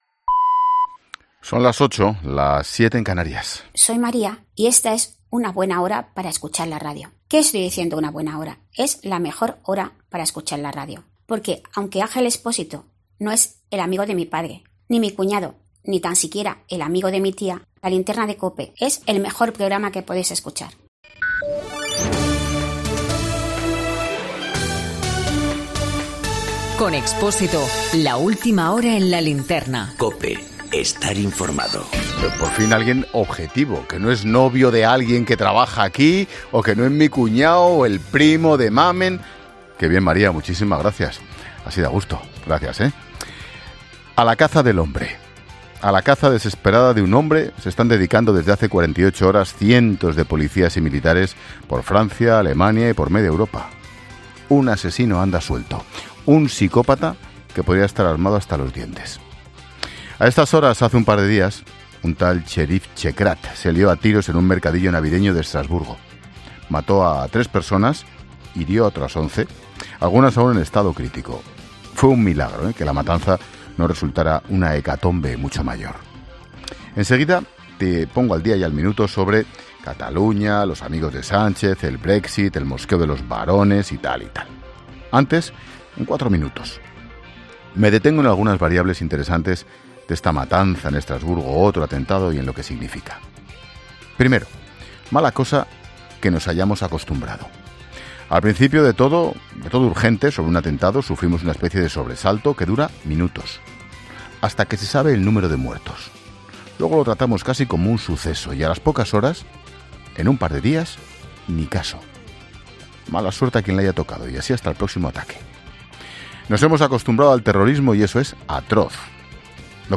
Monólogo de Expósito
El análisis de la actualidad de este jueves con Ángel Expósito en 'La Linterna'